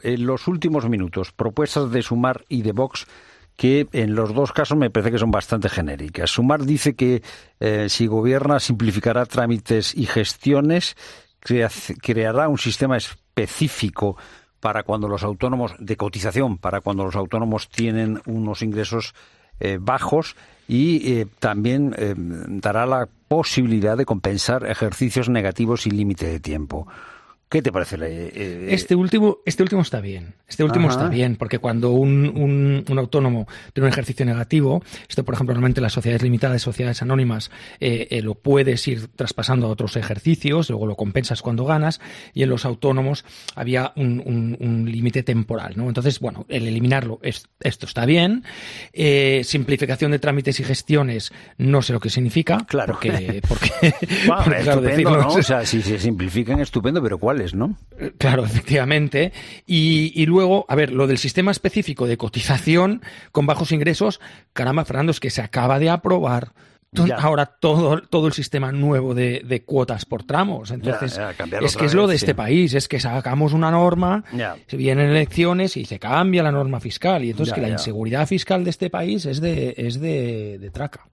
Análisis de Trias de Bes